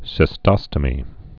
(sĭ-stŏstə-mē)